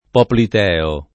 popliteo [ poplit $ o ]